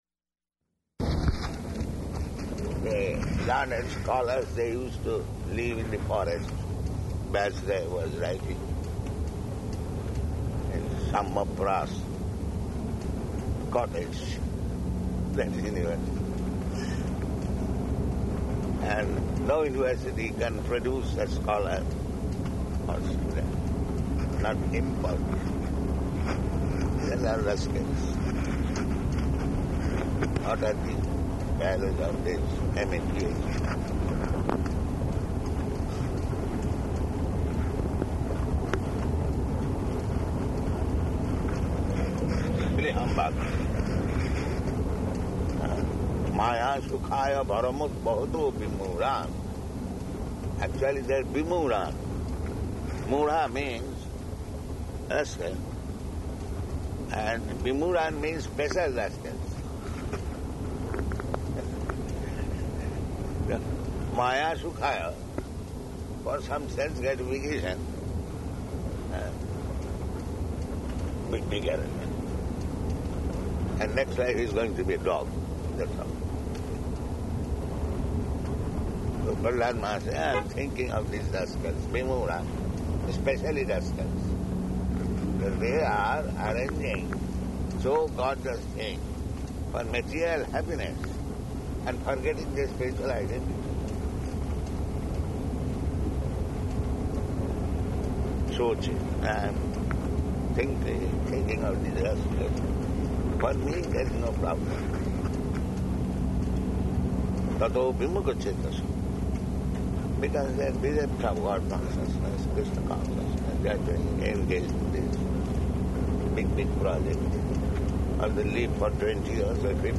Morning Walk --:-- --:-- Type: Walk Dated: February 2nd 1977 Location: Bhubaneswar Audio file: 770202MW.BHU.mp3 [in car] Prabhupāda: The learned scholars, they used to live in the forest.